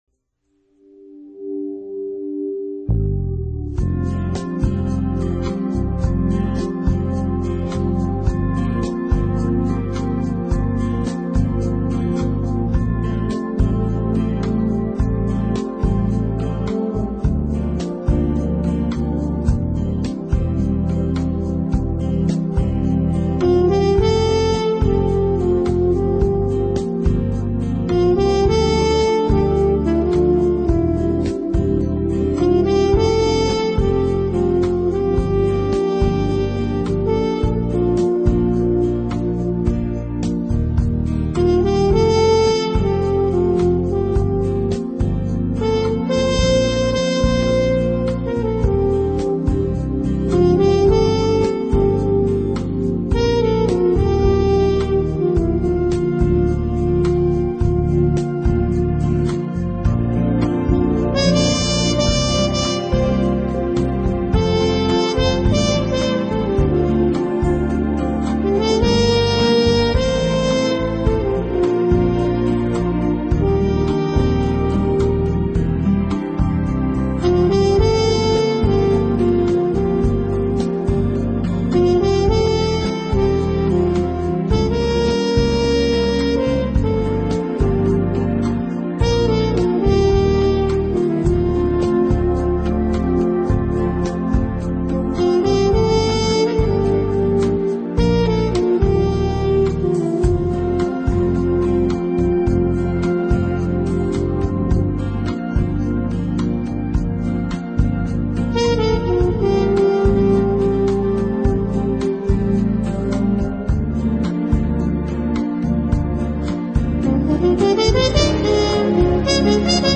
音乐类型：Jazz 爵士